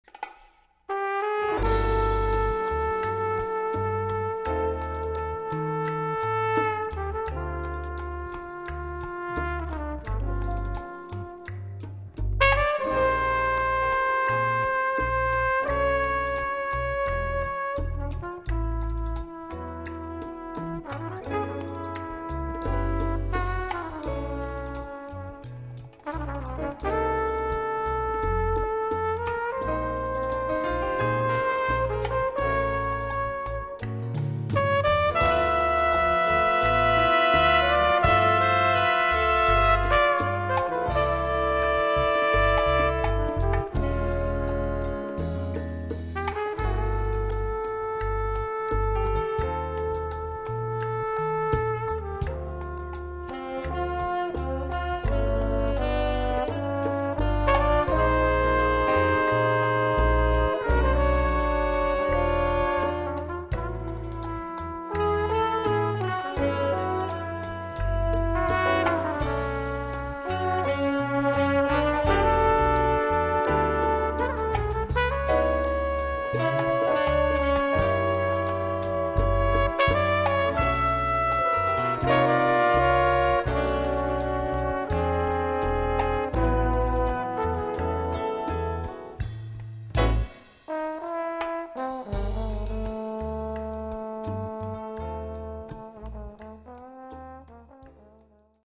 Category: little big band
Style: bolero
Solos: trumpet feature
Featured Instrument: trumpet